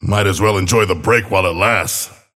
Abrams voice line - Might as well enjoy the break while it lasts.